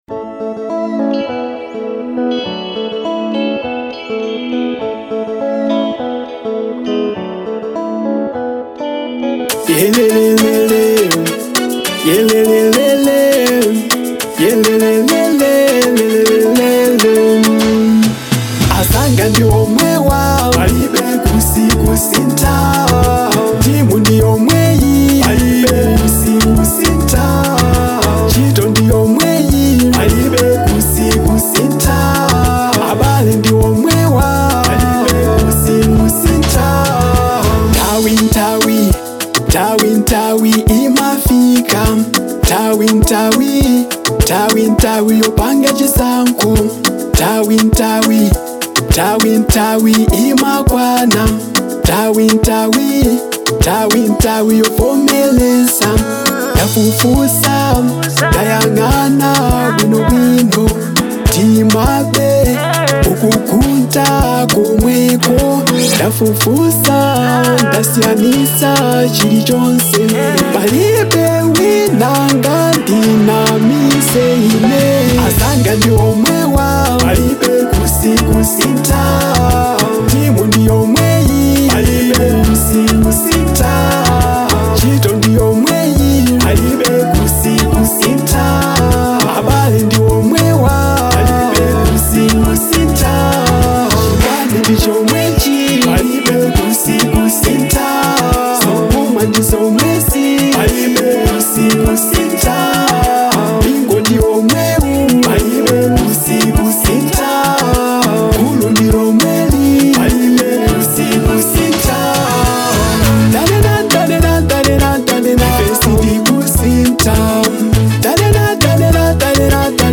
Genre : Local
a powerful and emotionally resonant track